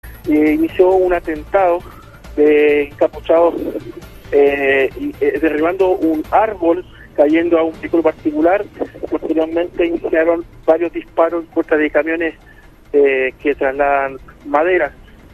Un auditor relató a Radio Bío Bío que cuando iba por la ruta, un grupo de encapuchados derribaron un árbol y posteriormente efectuaron varios disparos contra camiones que trasladan madera.